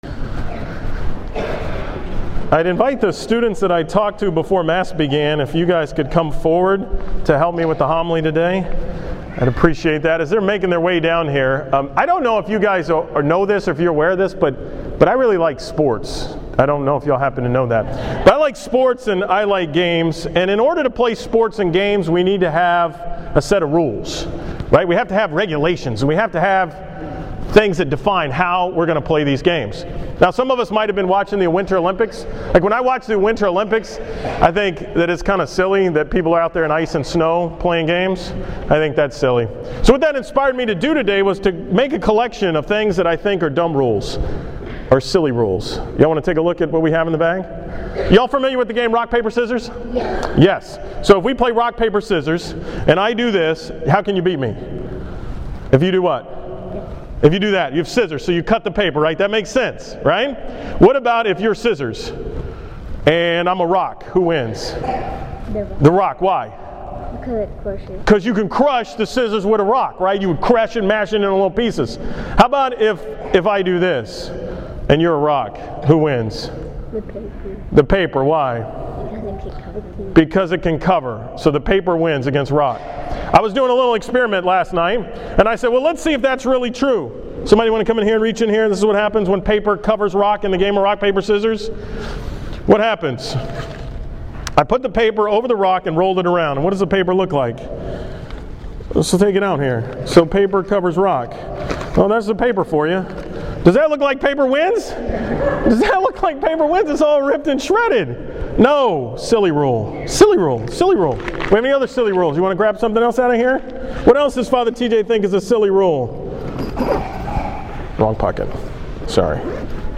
From the 9 am Mass on Sunday, February 16